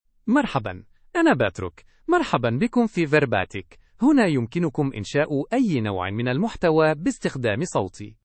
MaleArabic (Standard)
PatrickMale Arabic AI voice
Patrick is a male AI voice for Arabic (Standard).
Voice sample
Patrick delivers clear pronunciation with authentic Standard Arabic intonation, making your content sound professionally produced.